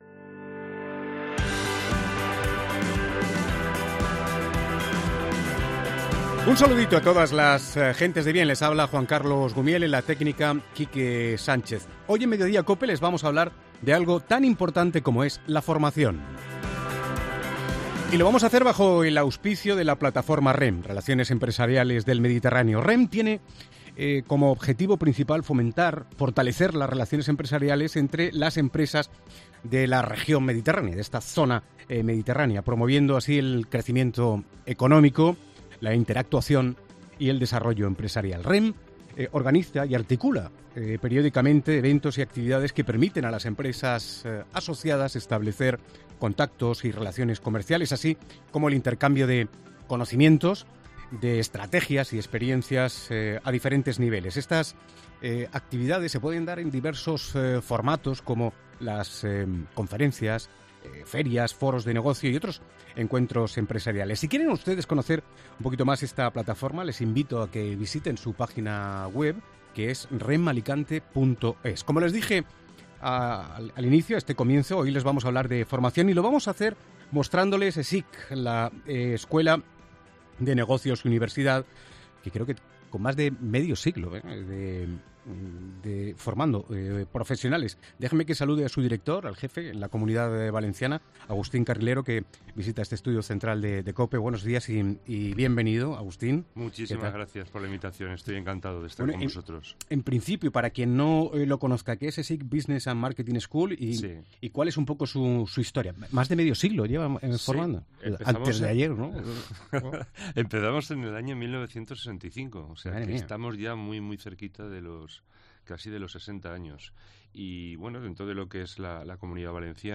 Entrevista REM: ESIC BUSINESS & MARKETING SCHOOL